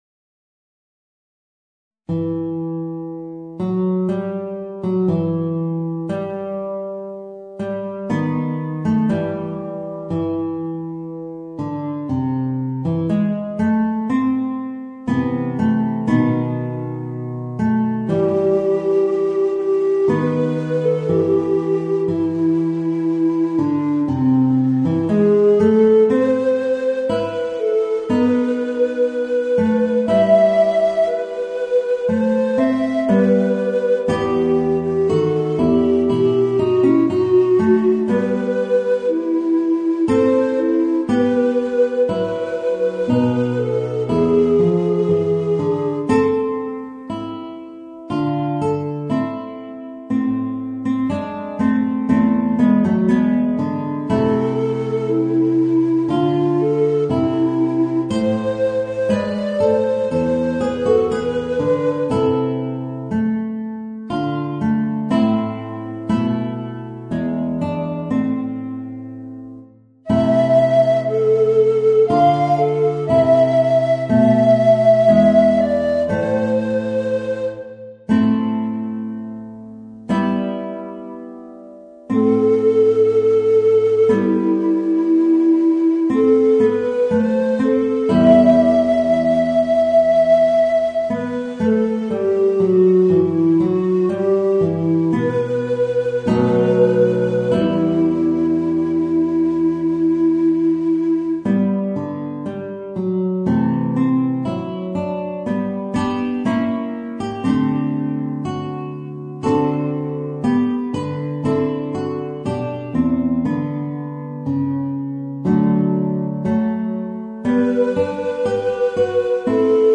Voicing: Guitar and Tenor Recorder